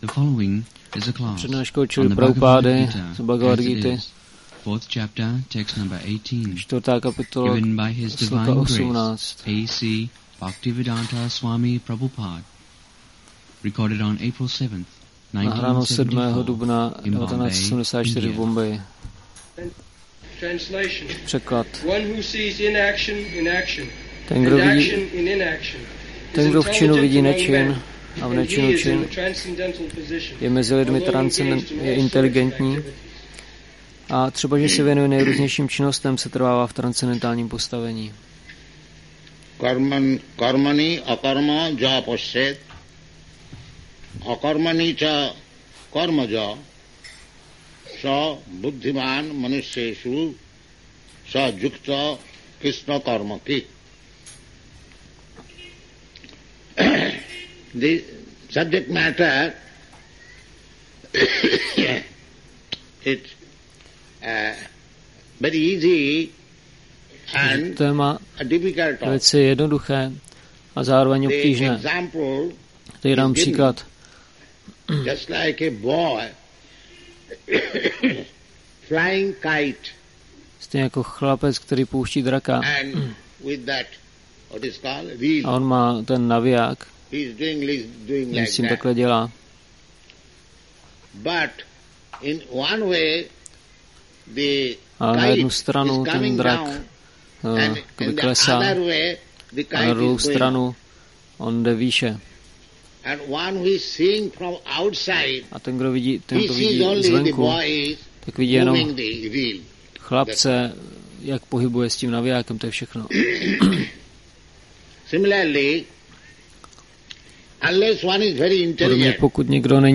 1974-04-07-ACPP Šríla Prabhupáda – Přednáška BG-4.18 Bombay